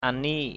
/a-ni:ʔ/ (d.) mai, thuổng, xà beng = bêche, tarière. spade.